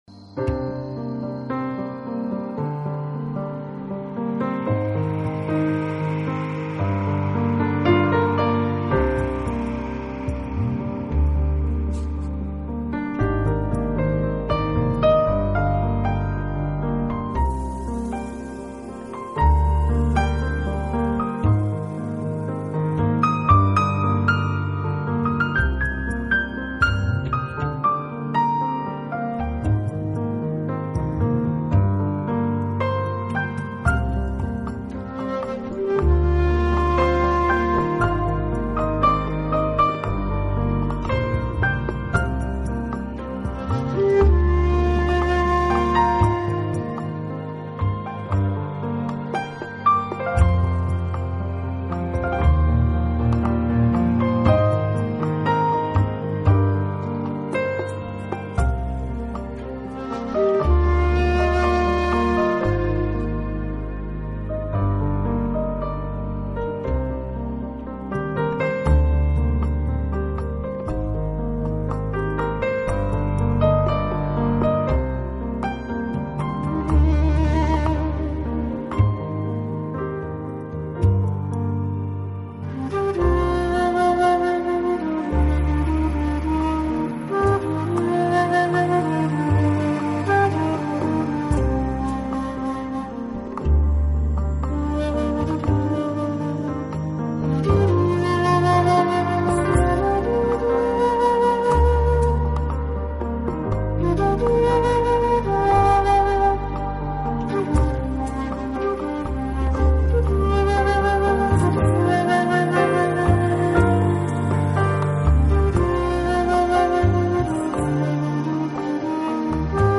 【钢琴VS长笛】